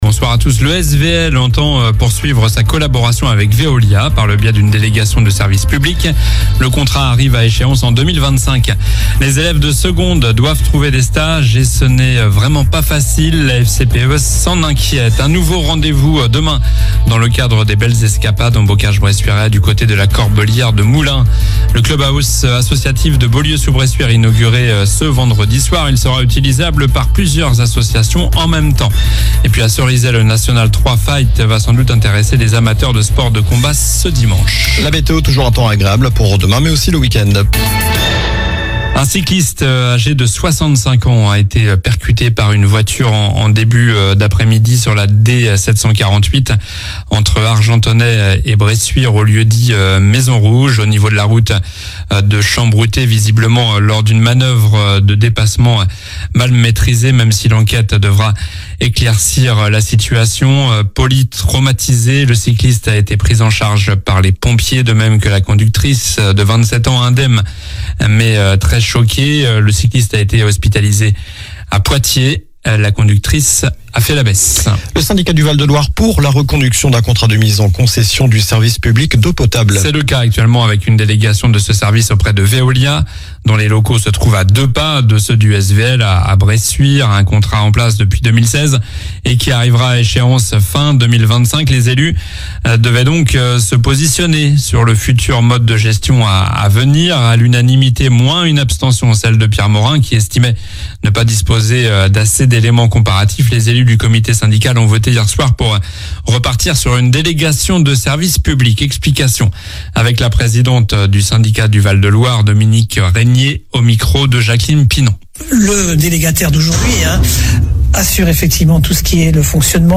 Journal du jeudi 06 juin (soir)